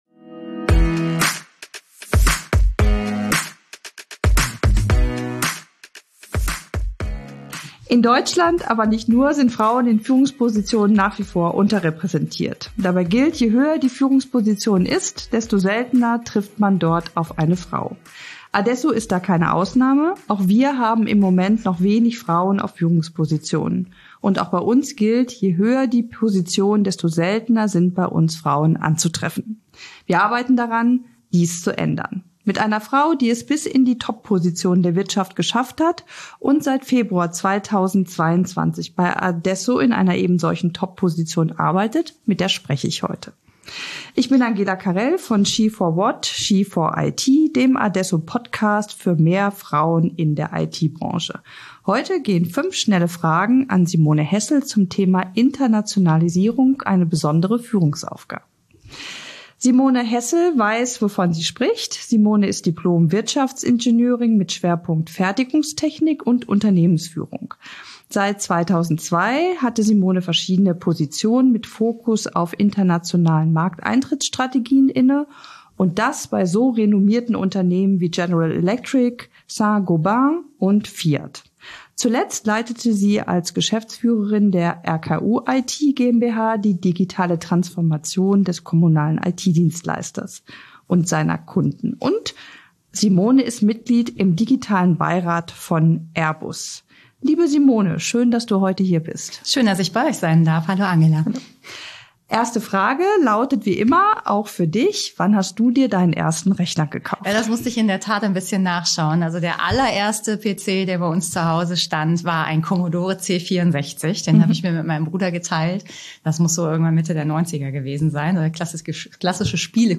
In unserem She for IT Podcast geht es um Themen rund um IT und digitale Transformation – und das mit der besonderen weiblichen Note. Unsere Moderatorinnen interviewen IT-Frauen, die etwas zu sagen haben. Locker, spannend und kurzweilig.